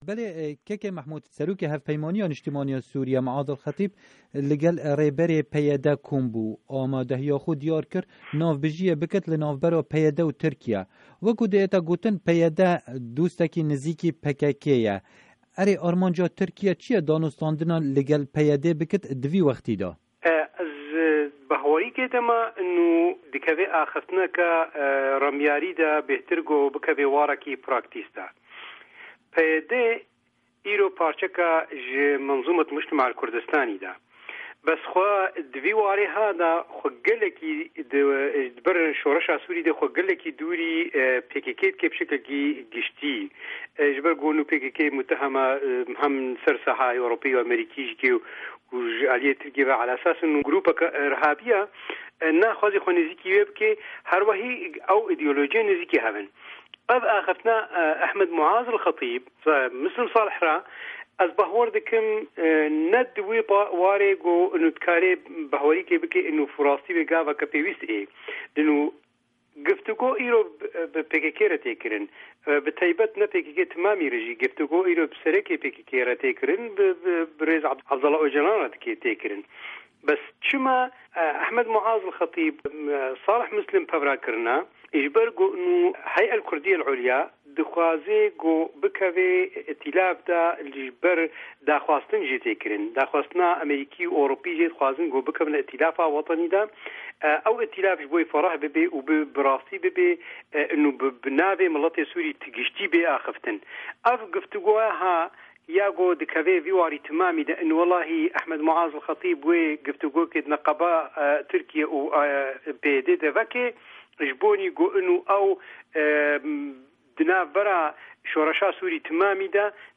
Hevpeyvîn bi Mehmûd Ebbas re